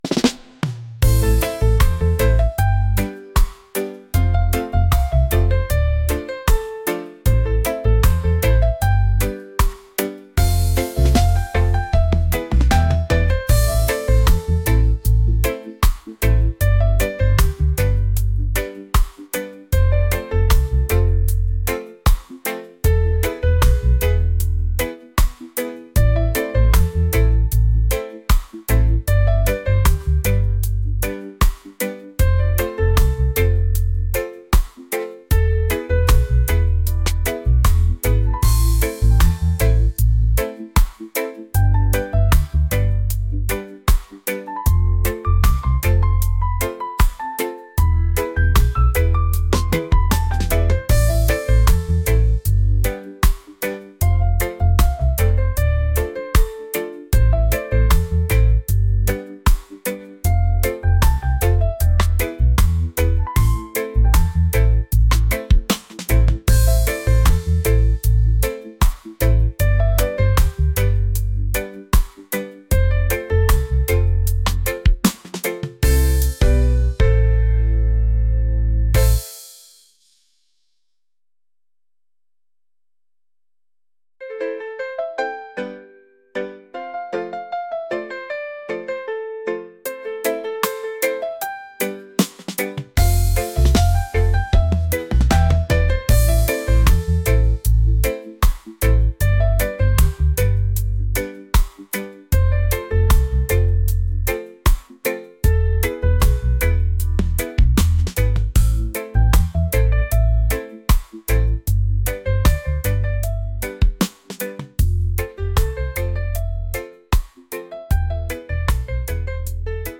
reggae | laid-back | smooth